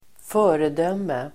Uttal: [²f'ö:redöm:e]